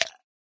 ice.ogg